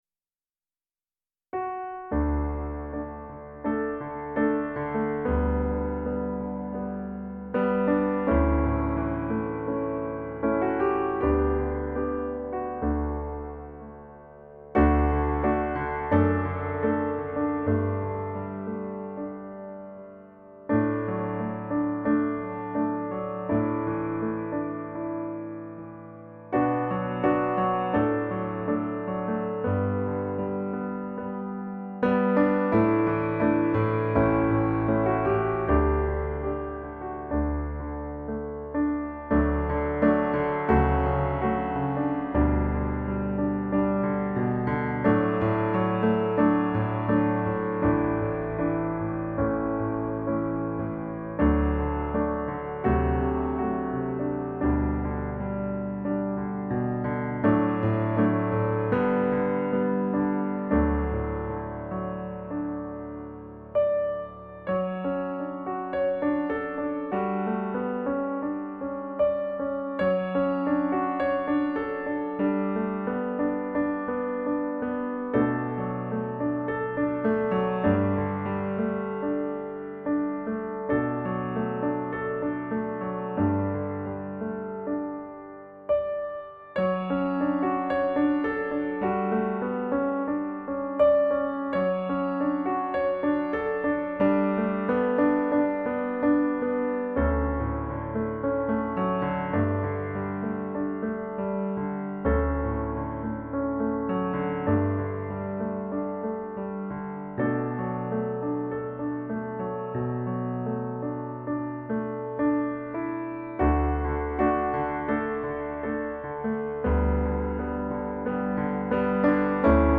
This is a piano version of a choral setting of the song of Mary (often called the Magnificat) from the Gospel of Luke.